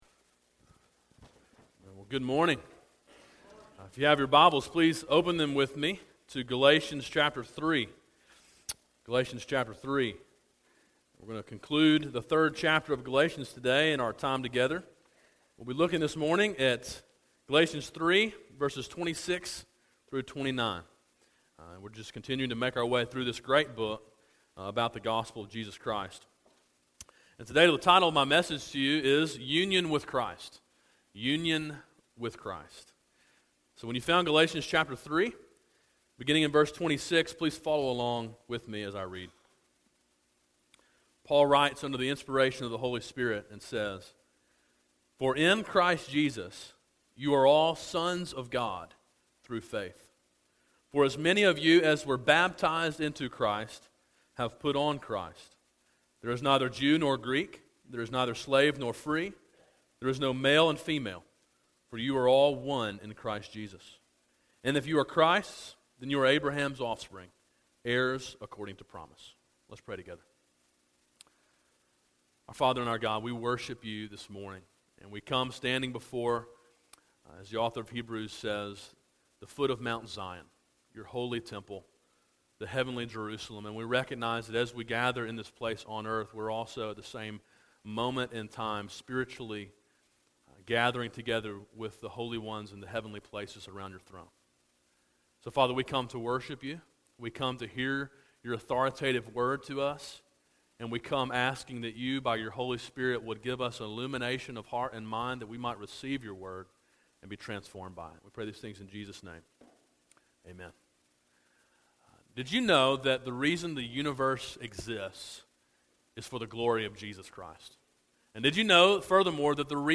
A sermon in a series on Galatians titled Freedom: A Study of Galatians.